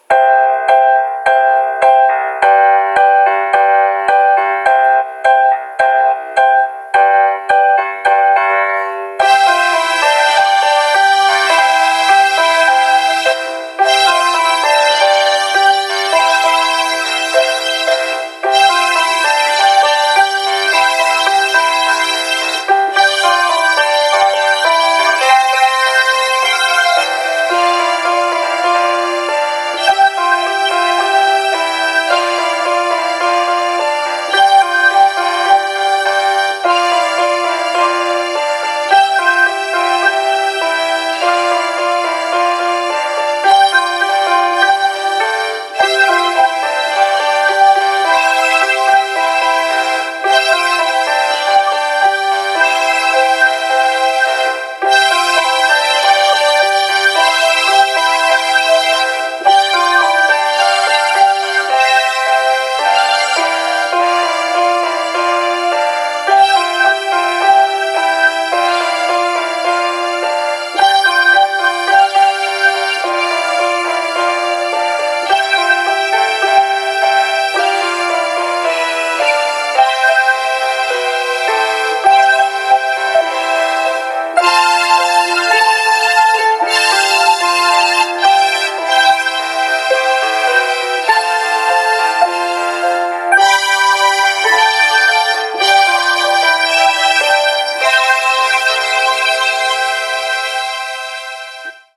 My Little Brother Melody Mixed